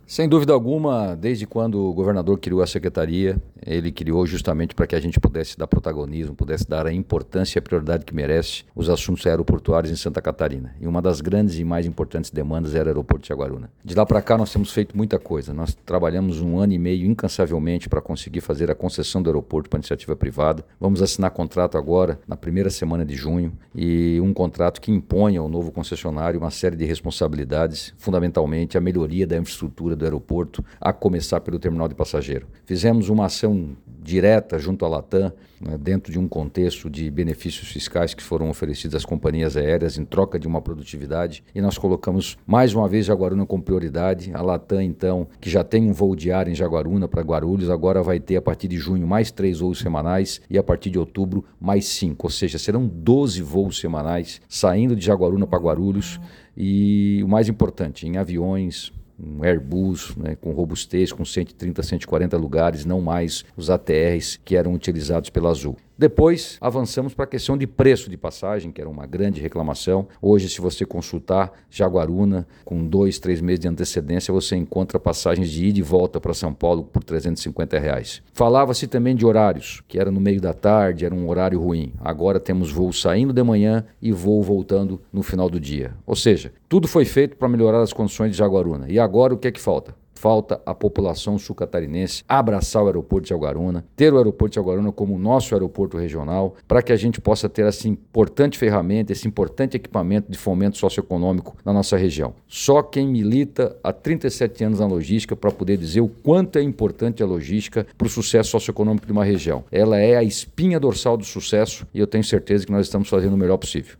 O secretário de Estado de Portos, Aeroportos e Ferrovias, Beto Martins, explica o trabalho desenvolvido para a concessão do Aeroporto de Jaguaruna e para a ampliação de voos e horários no equipamento: